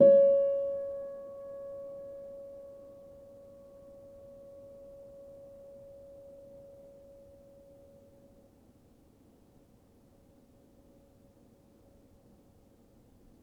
healing-soundscapes/Sound Banks/HSS_OP_Pack/Upright Piano/Player_dyn1_rr1_026.wav at main